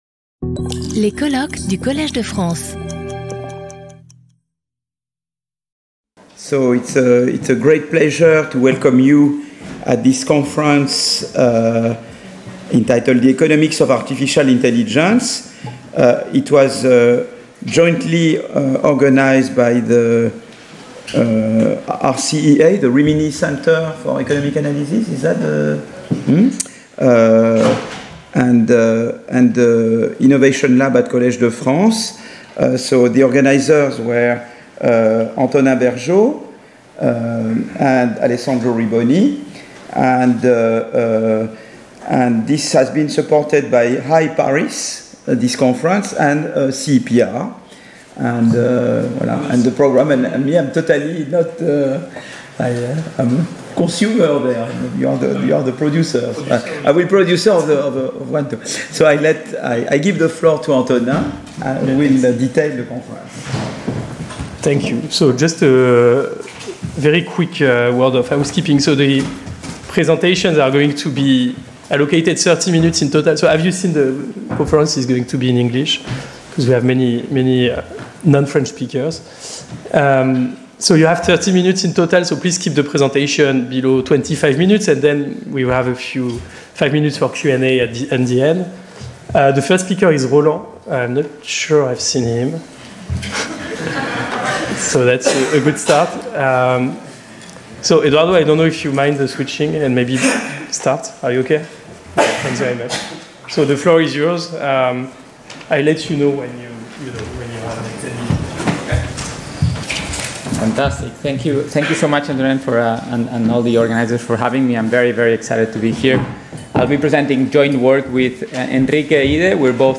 Lecture audio